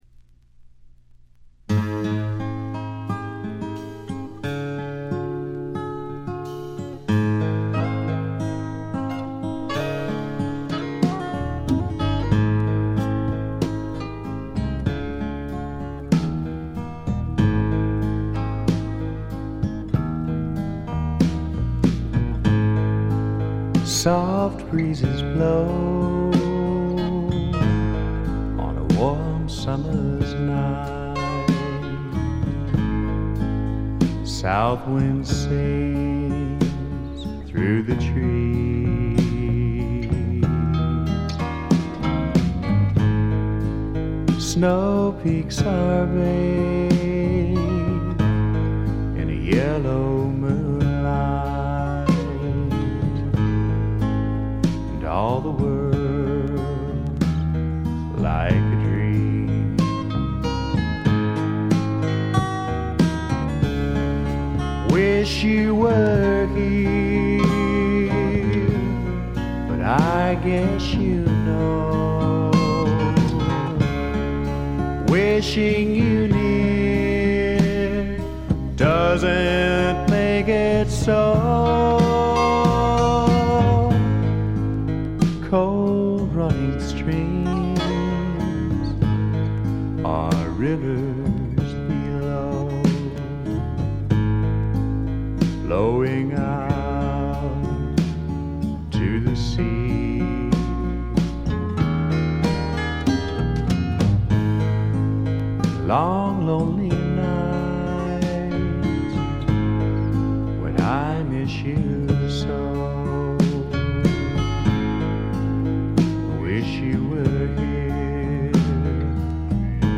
ほとんどノイズ感無し。
カントリー風味の曲とかファンキーな曲とかもありますが、クールでちょいメロウな曲調が特に素晴らしいと思います。
試聴曲は現品からの取り込み音源です。
Recorded at - Real To Reel , Garland, Texas